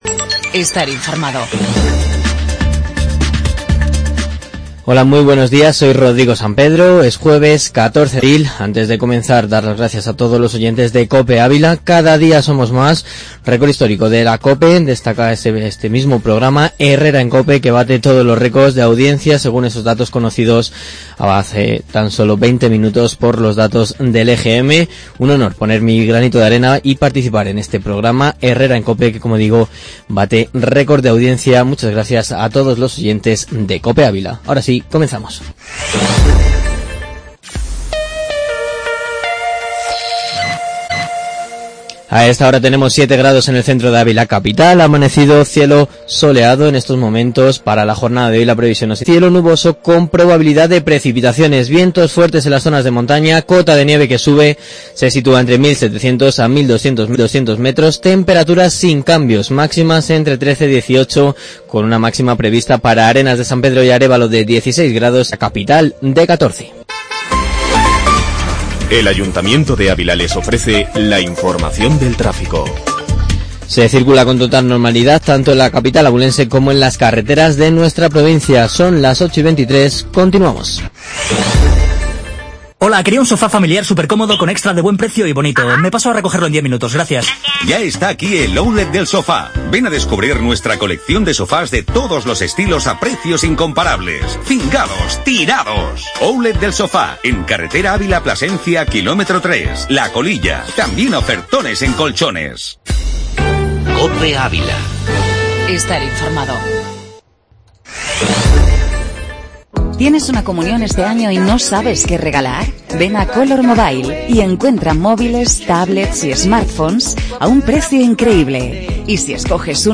Informativo matinal en 'Herrera en Cope'